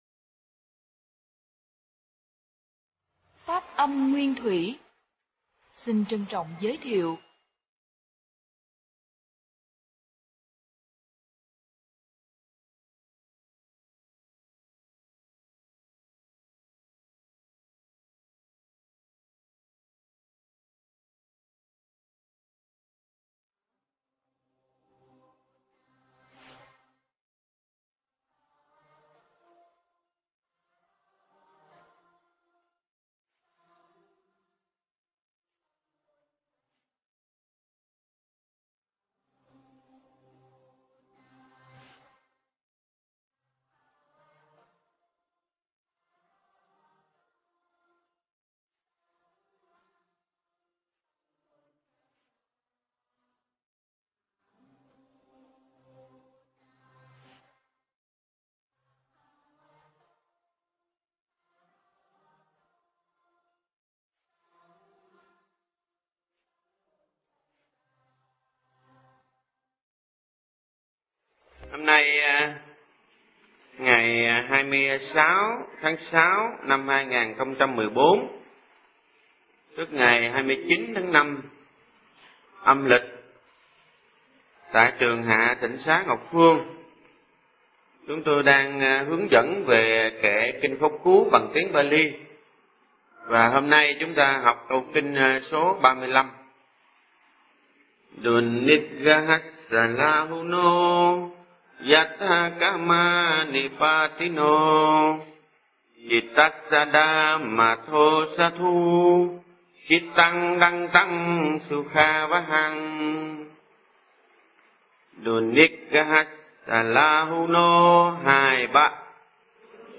Nghe Mp3 thuyết pháp Kinh Pháp Cú 35